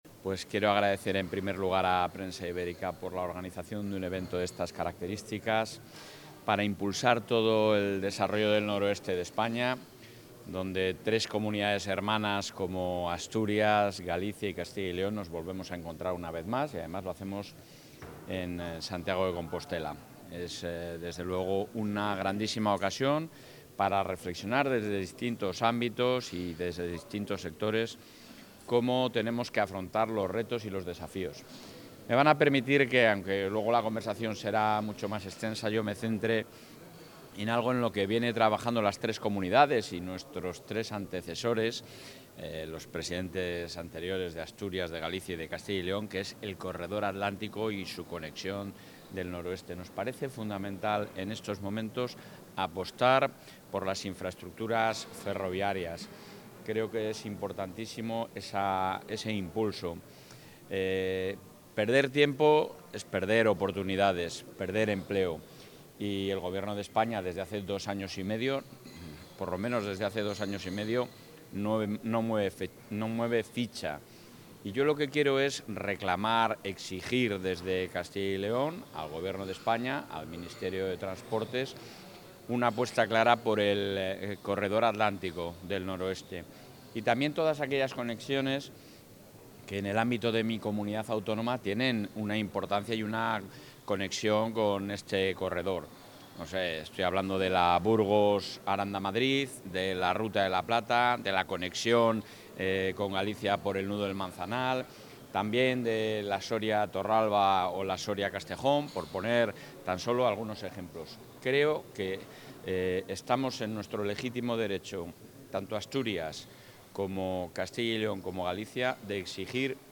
Declaraciones del presidente de la Junta.
El presidente de la Junta ha destacado en el Foro Noroeste, celebrado en Santiago de Compostela, el compromiso del Ejecutivo autonómico para seguir consolidando los mejores servicios públicos en toda la Comunidad, impulsar la industrialización y la innovación, apoyar al campo y fijar población en el medio rural.